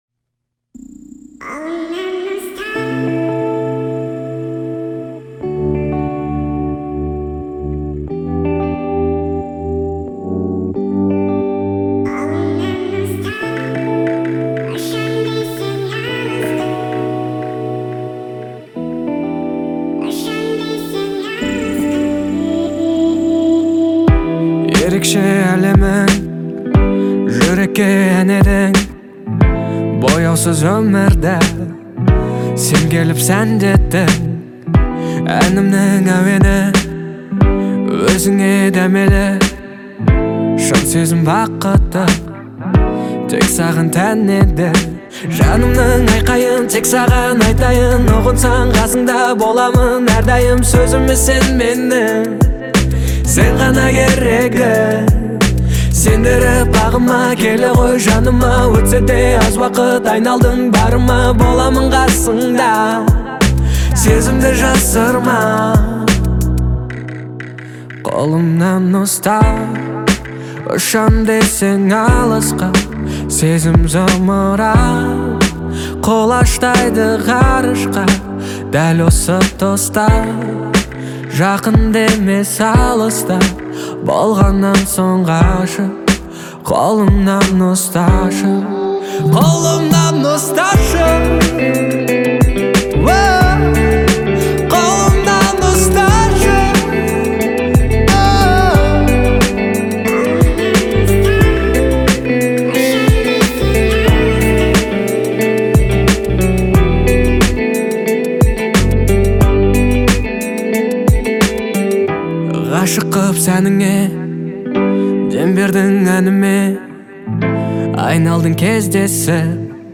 Неплохая балладка)